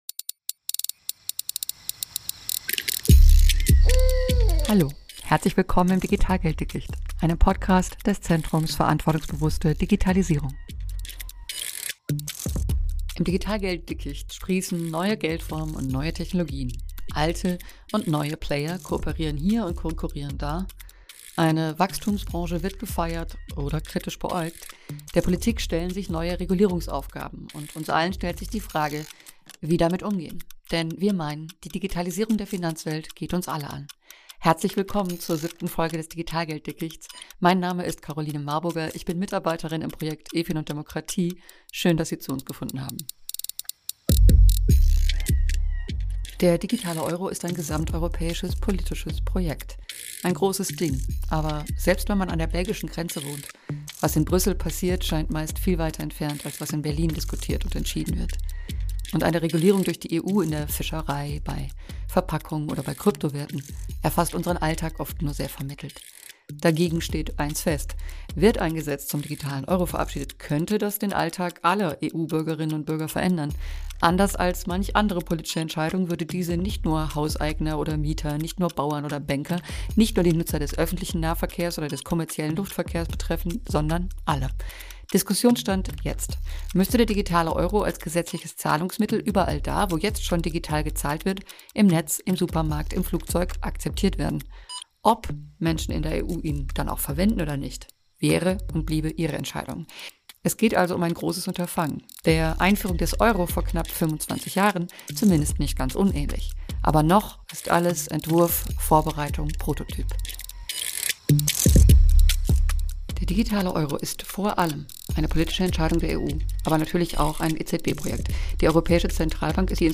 Diesen Fragen widmen wir uns im Gespräch mit EU-Parlamentarier:innen.